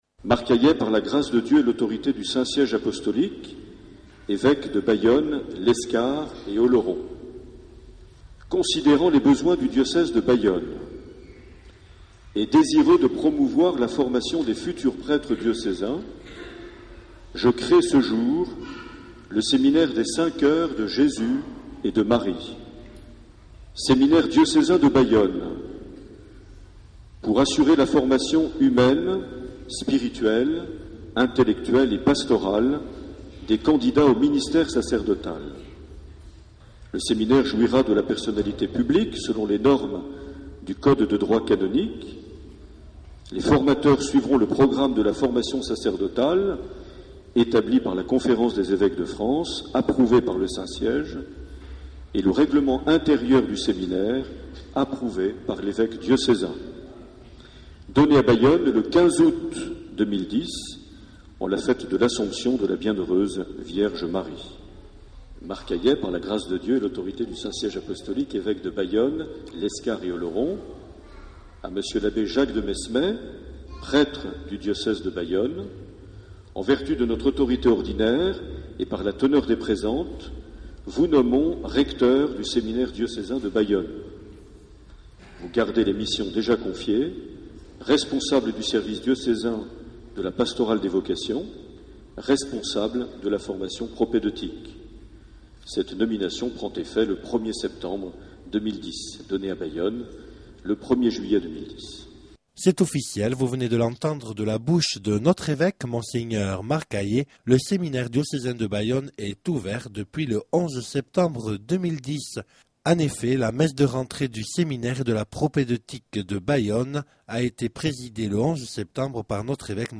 11 septembre 2010 - Bayonne église Saint Amand - Messe de rentrée du séminaire et de la propédeutique
Accueil \ Emissions \ Vie de l’Eglise \ Evêque \ Les Homélies \ 11 septembre 2010 - Bayonne église Saint Amand - Messe de rentrée du (...)
Une émission présentée par Monseigneur Marc Aillet